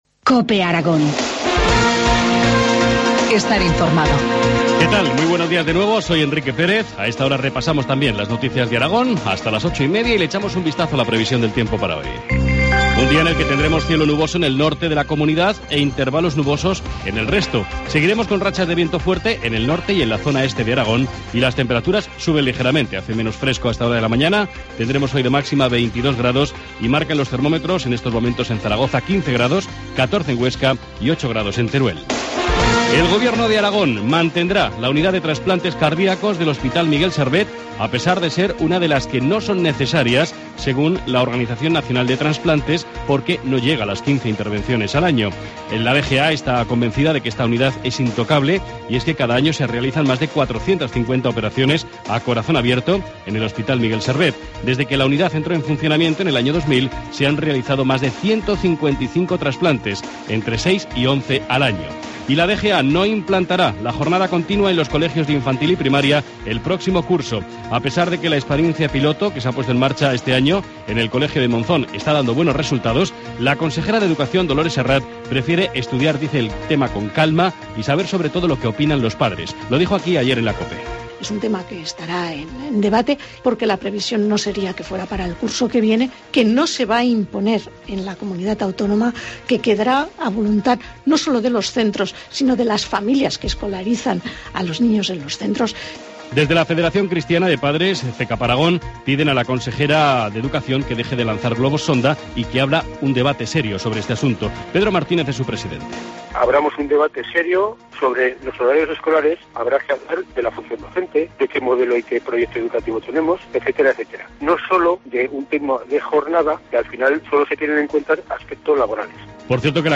Informativo matinal, martes 5 de noviembre, 8.25 horas